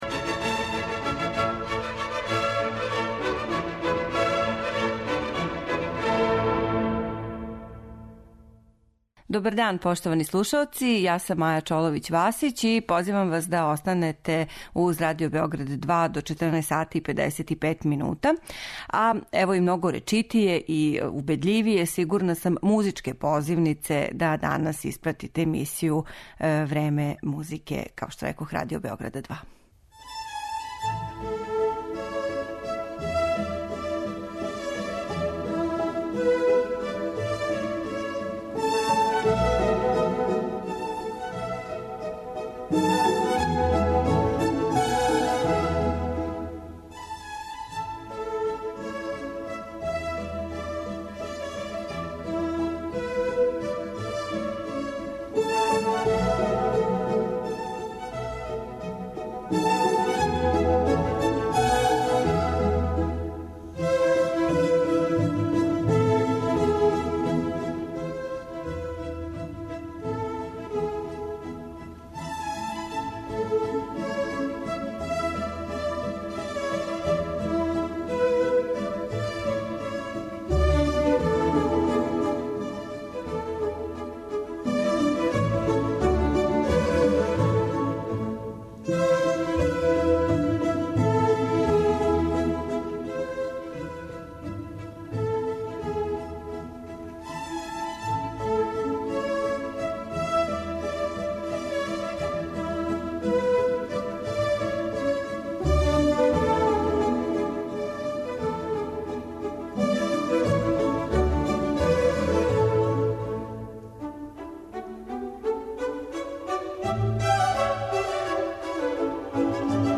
Фрагменти из познатих дела Луиђија Бокеринија